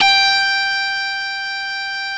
* strum effect implemented
BendG5.wav